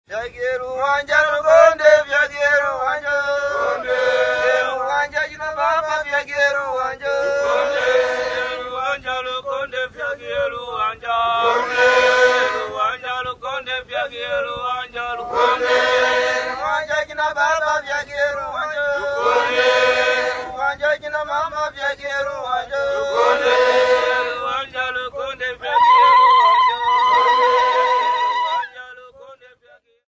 Swahili Men and Women
Folk music--Africa
Field recordings
Africa Zambia Not specified f-za
sound recording-musical